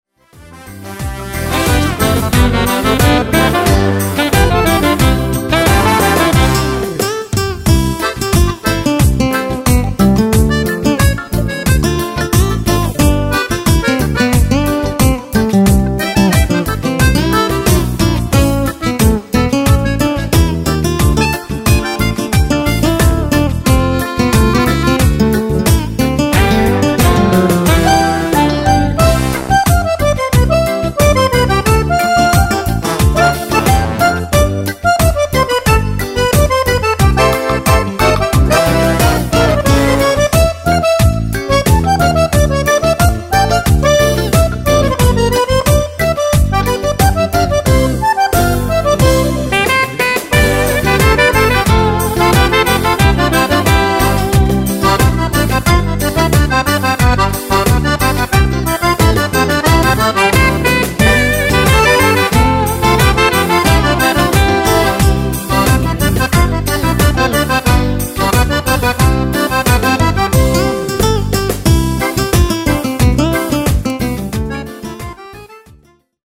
Bajon-reggae
Fisarmonica